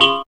3707R DX7CHD.wav